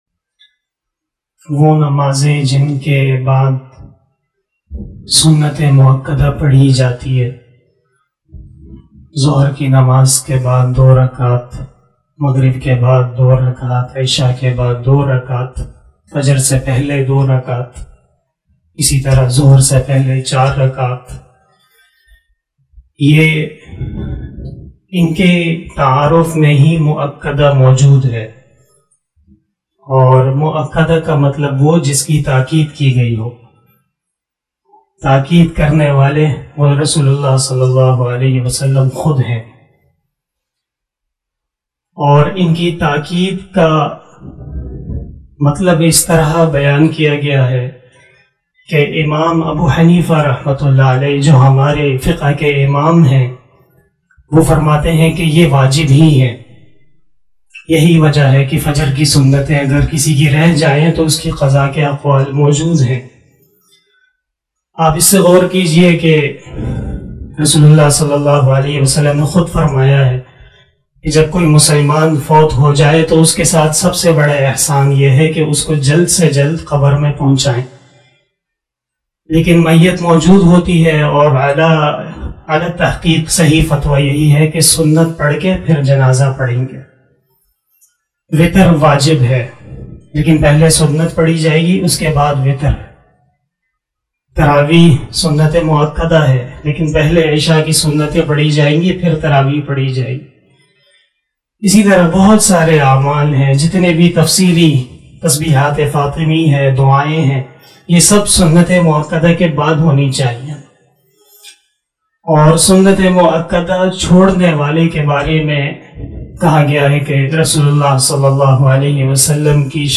038 After asar Namaz Bayan 01 August 2021 (22 Zilhajjah 1442HJ) Sunday
بیان بعد نماز عصر